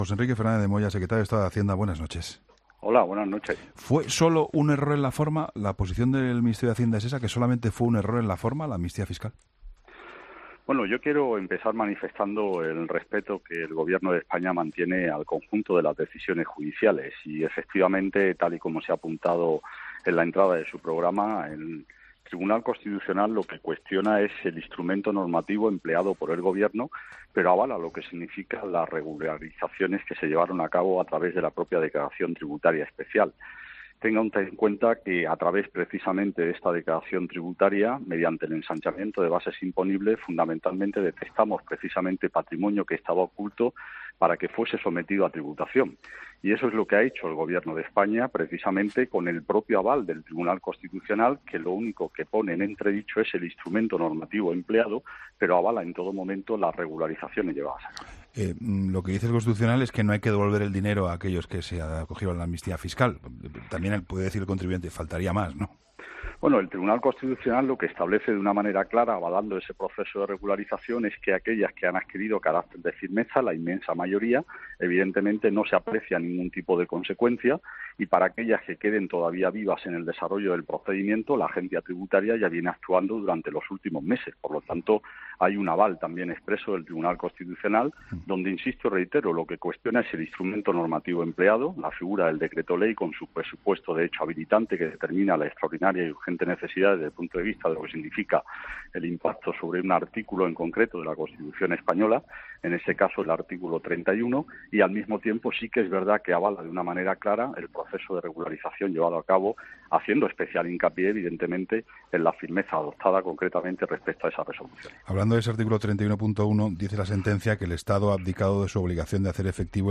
ESCUCHA LA ENTREVISTA COMPLETA | José Enrique Fernández de Moya, en 'La Linterna'